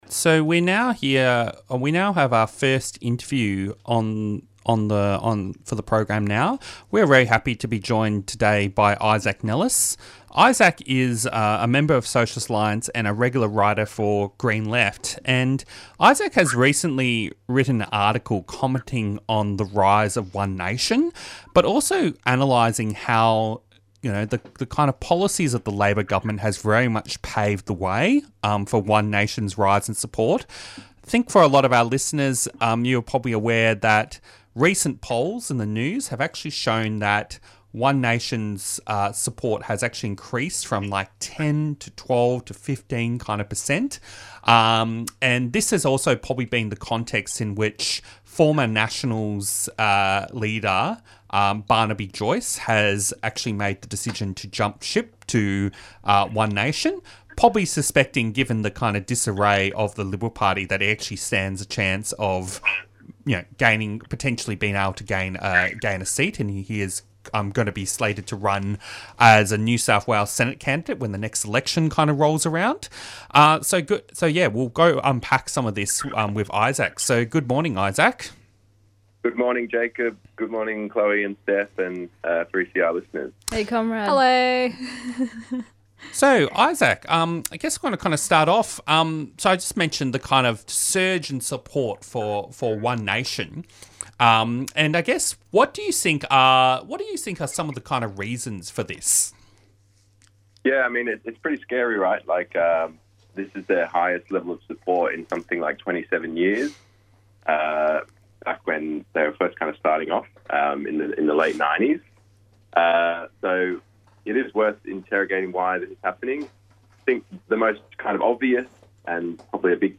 Discussion between the presenters about Trumps threats to Venezuela drawing on newsreports from Al-jazeera .